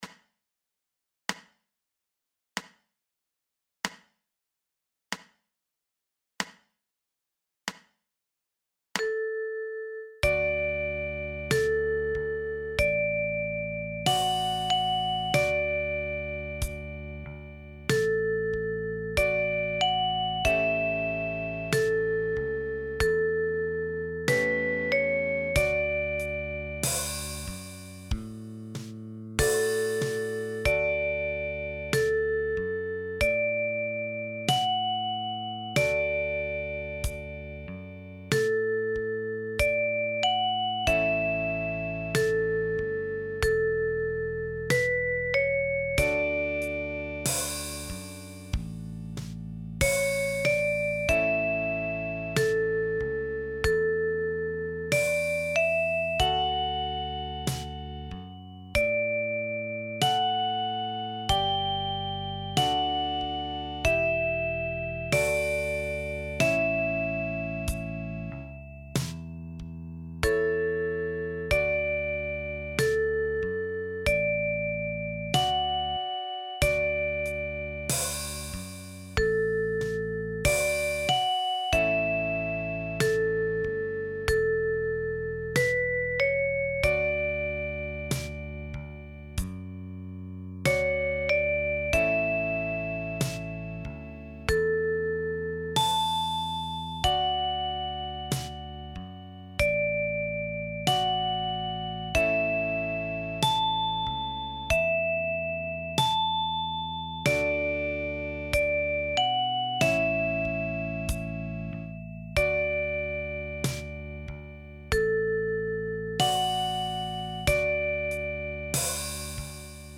deutsche Volkslider für die Mandoline – Band 2 + Sounds